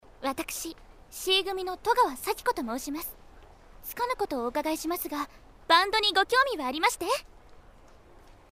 来自萌娘共享 跳转至： 导航 、 搜索 文件 文件历史 文件用途 全域文件用途 BanGDreamItsMyGOAnimeVoice090316.mp3  （MP3音频文件，总共长8.5秒，码率128 kbps） 文件说明 授权协议 本作品仅以介绍为目的在此百科中以非盈利性方式使用，其著作权由原著作权人保留。